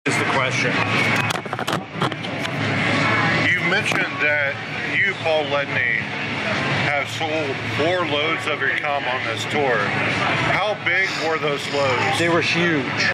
profanatica_interview.mp3